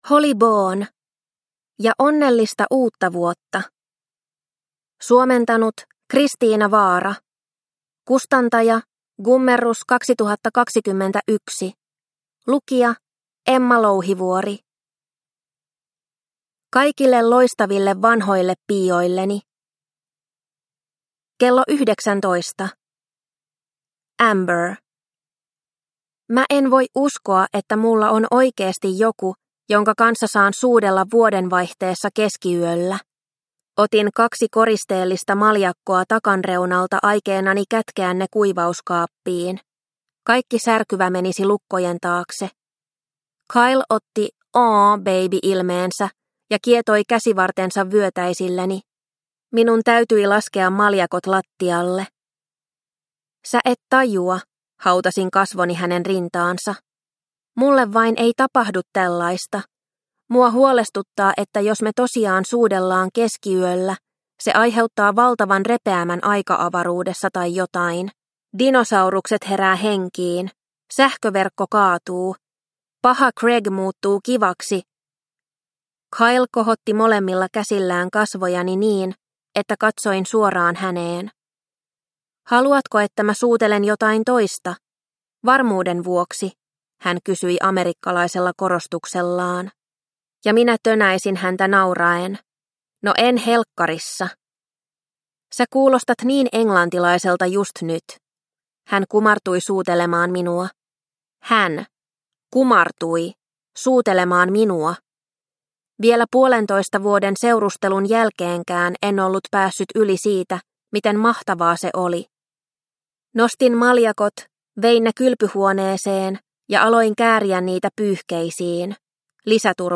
...ja onnellista uutta vuotta? – Ljudbok – Laddas ner